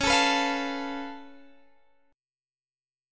Db+7 chord